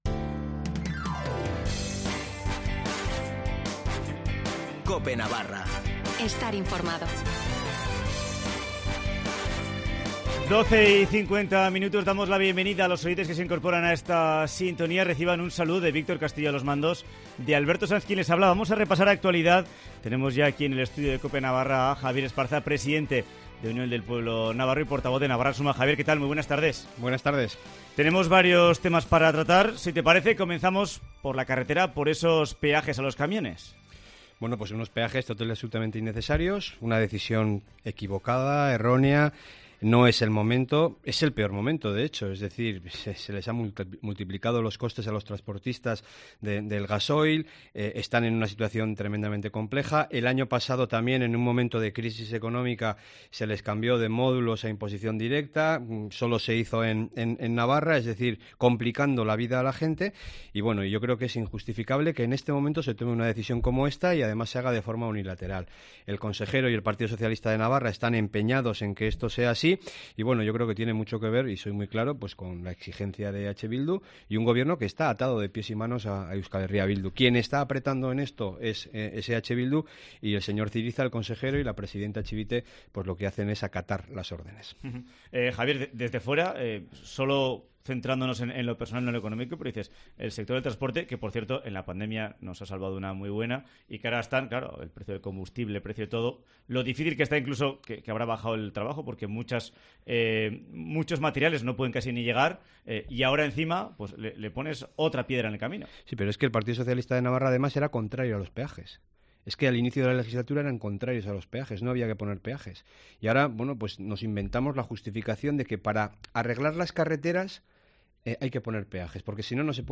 Entrevista a Javier Esparza en COPE Navarra